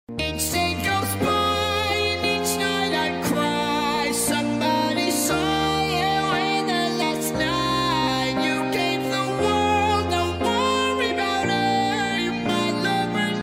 robot sing whit dog Michelo2.0 sound effects free download